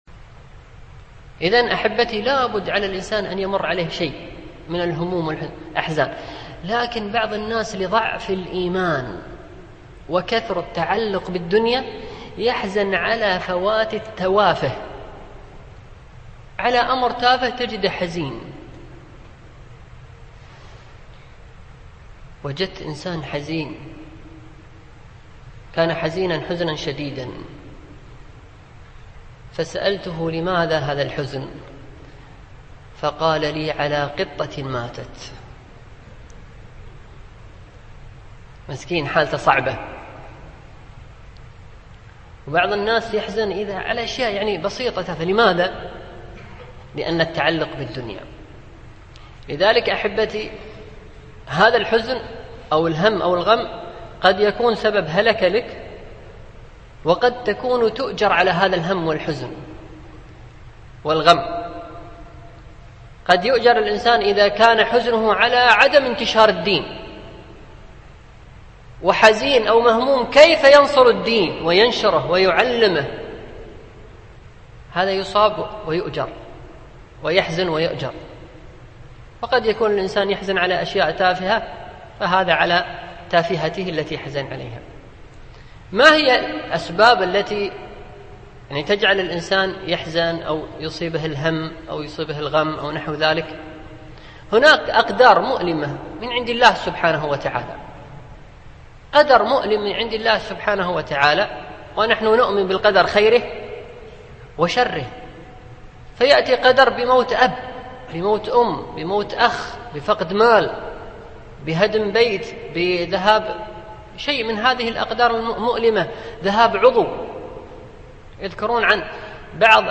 لقاء مفتوح